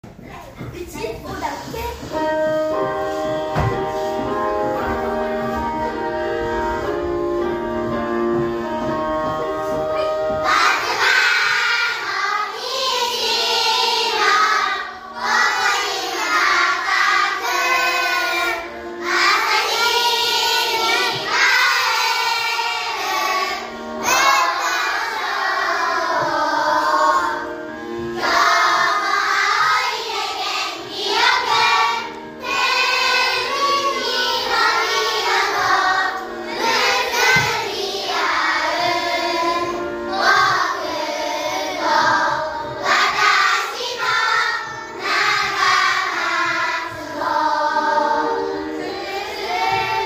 0519 ♪1年校歌♪
こうか1年.mp3